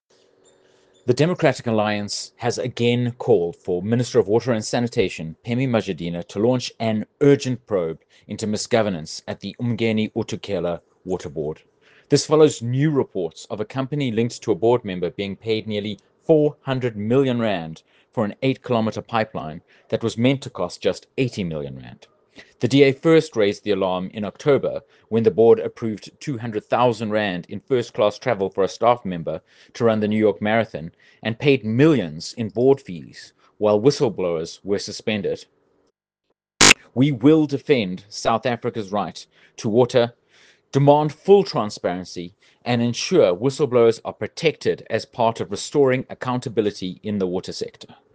soundbite by Stephen Moore MP.